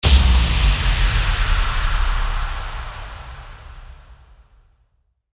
FX-1478-IMPACT
FX-1478-IMPACT.mp3